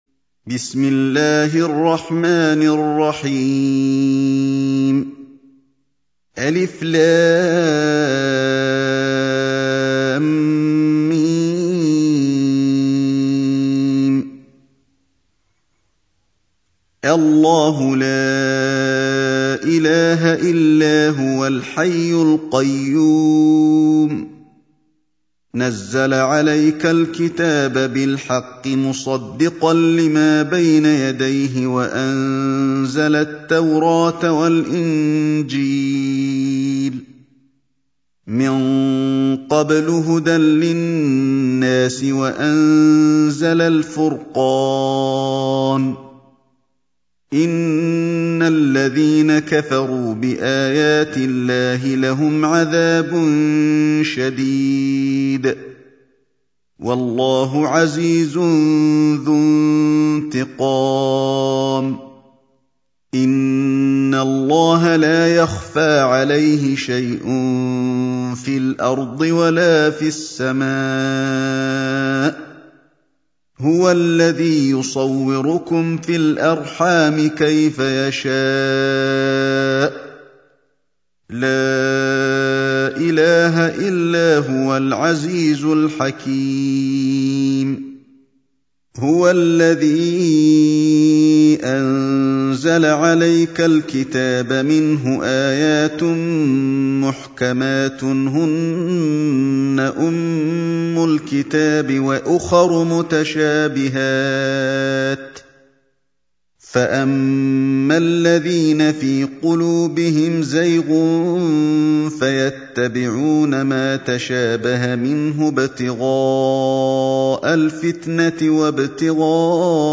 سورة آل عمران | القارئ علي الحذيفي